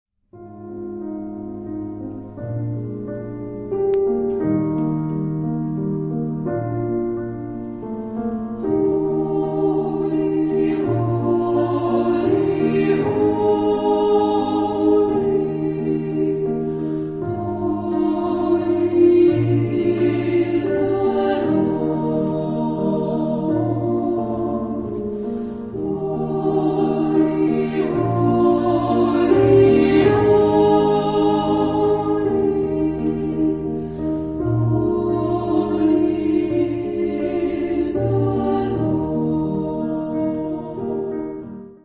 Genre-Style-Form: Sacred ; Section of the mass
Mood of the piece: adagio ; expressive
Type of Choir: SATB  (4 mixed voices )
Instrumentation: Piano  (1 instrumental part(s))
Tonality: E flat major
sung by The Oxford Choir conducted by Bob Chilcott